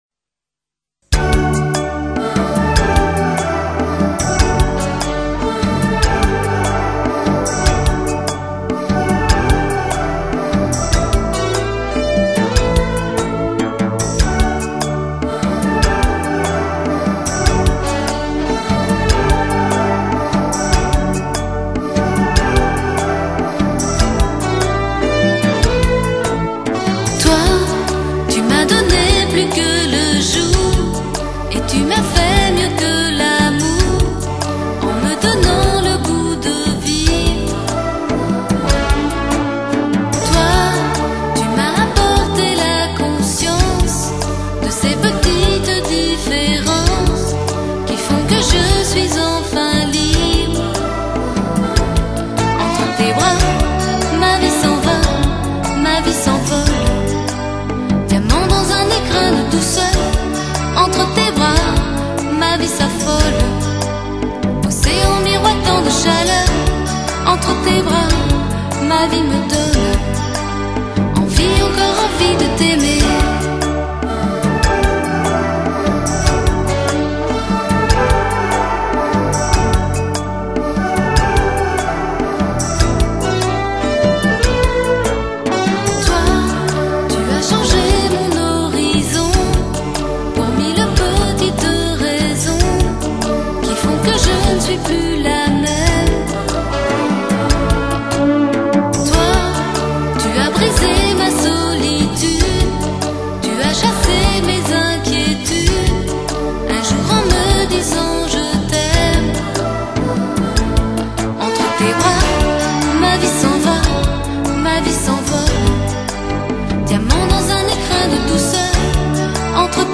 감미로운 샹송